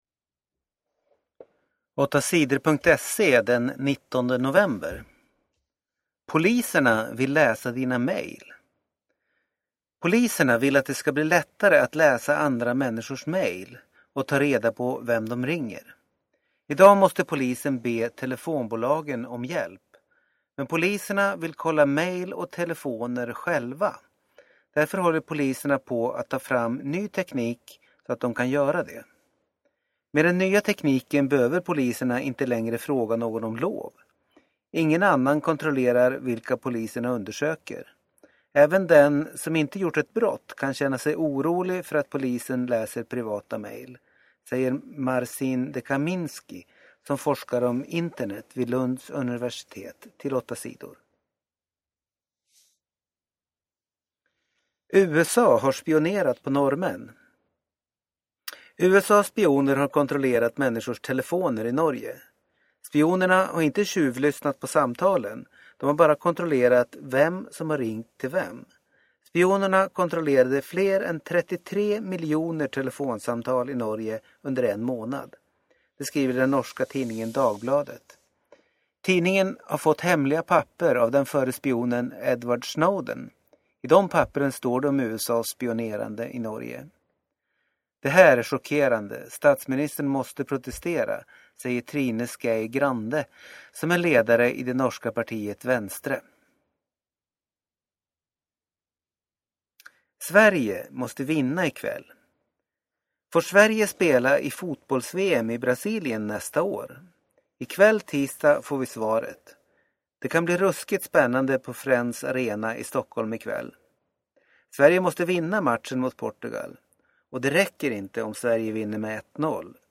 Inlästa nyheter 19 november